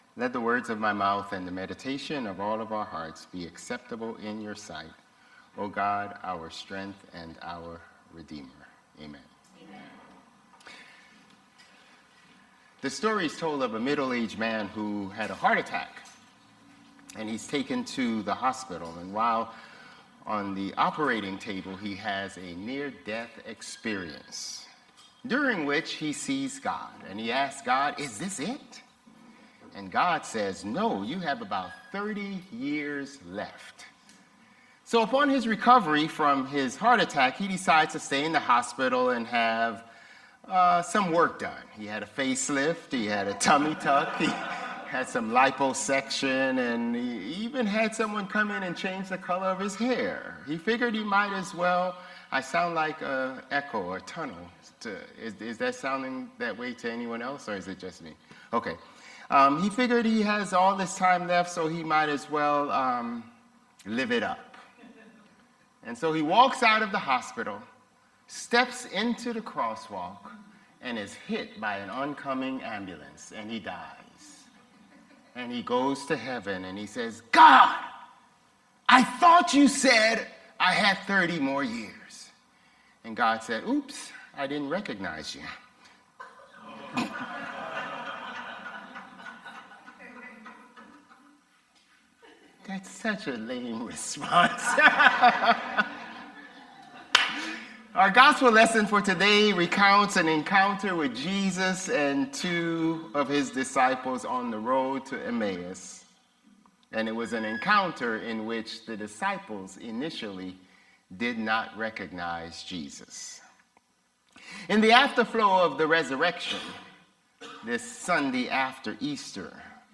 Sermons | Bethel Lutheran Church
April 27 Worship